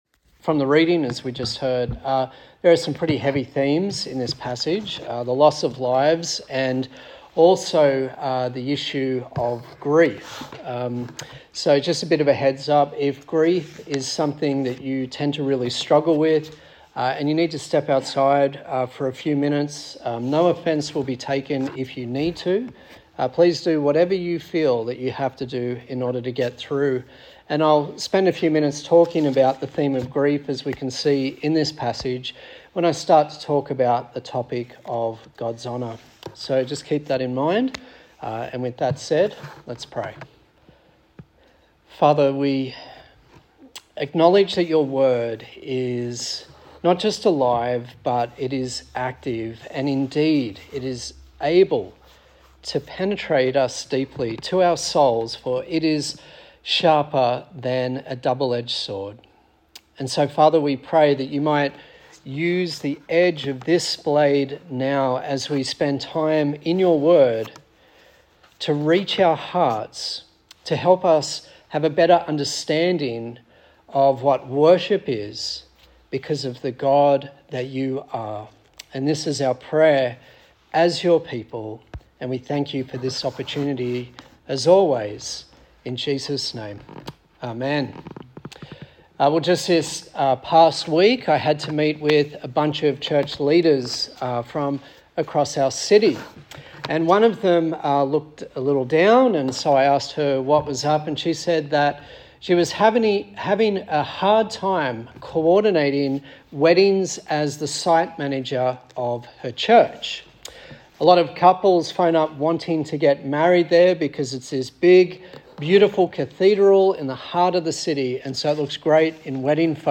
Service Type: Sunday Service A sermon